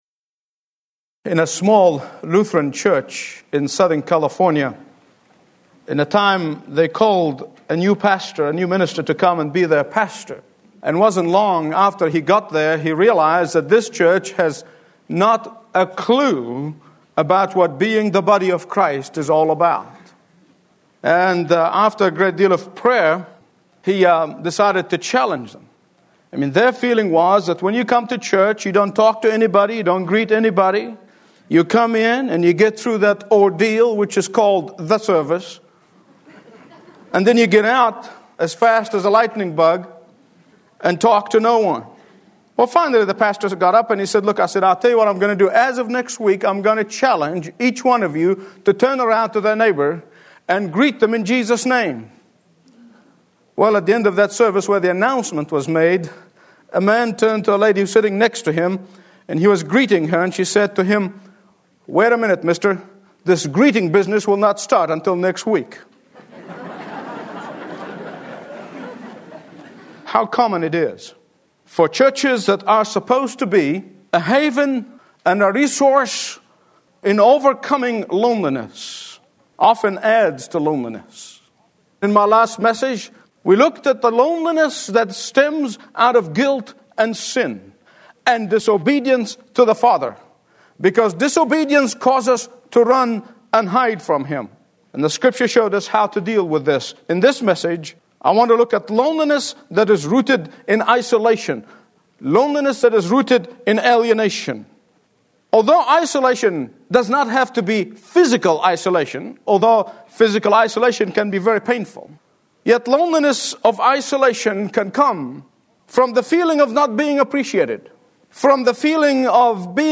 Listen to Dr. Michael Youssef's Daily Teaching on Loneliness from Isolation in HD Audio.